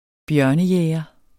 Udtale [ ˈbjɶɐ̯nə- ]